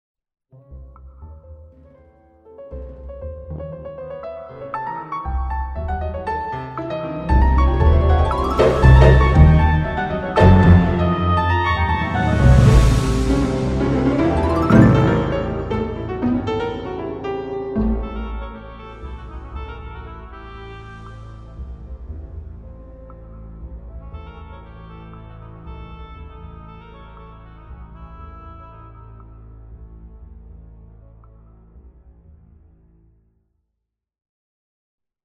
Original music introduction